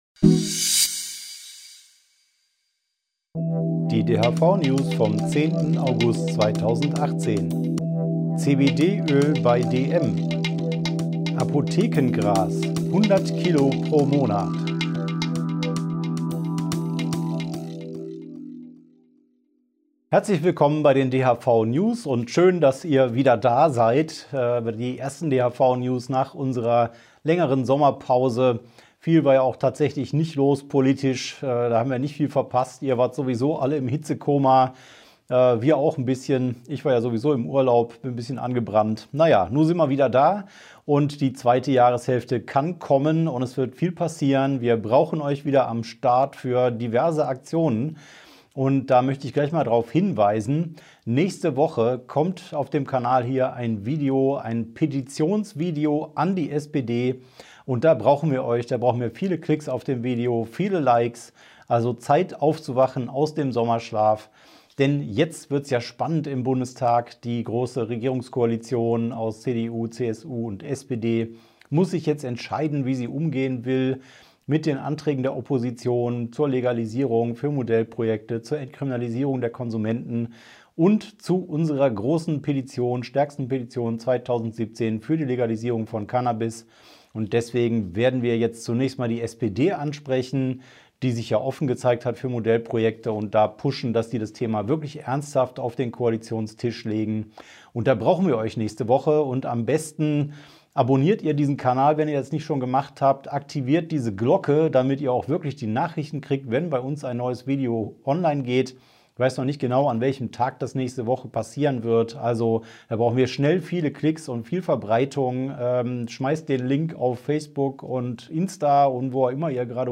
DHV-Video-News #174 Die Hanfverband-Videonews vom 10.08.2018 Die Tonspur der Sendung steht als Audio-Podcast am Ende dieser Nachricht zum downloaden oder direkt hören zur Verfügung.